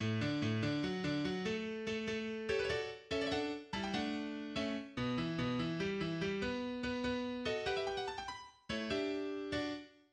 Genre Sonate pour piano
1. Allegro, en ré majeur, à
Introduction de l'Allegro: